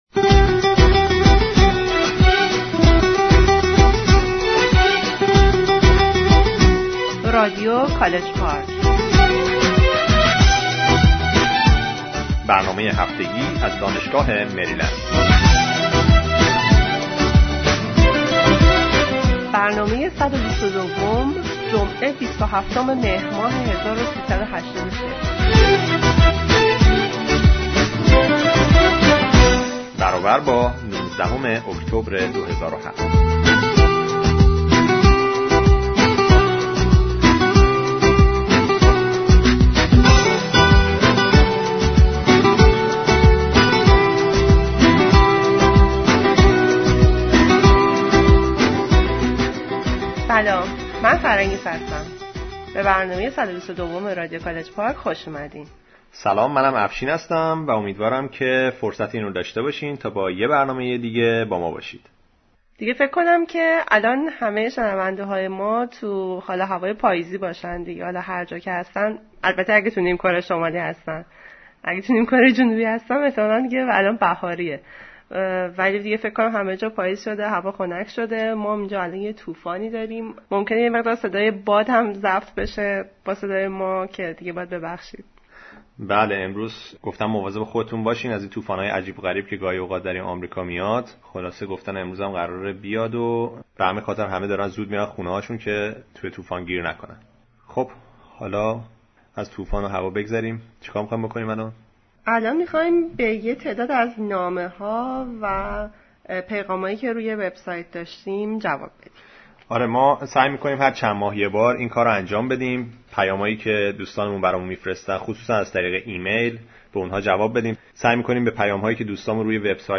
Weekly News
Musicfile at the end